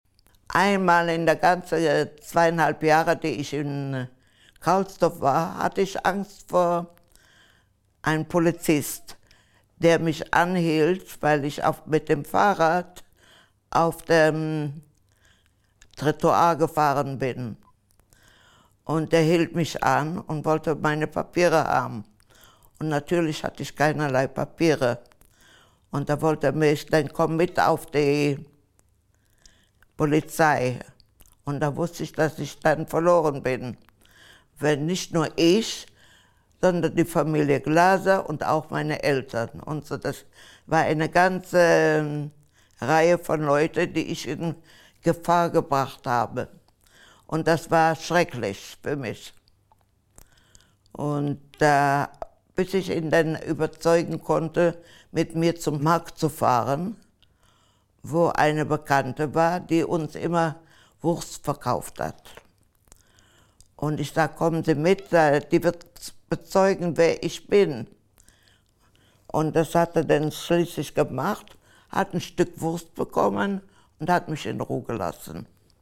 Zeitzeugeninterview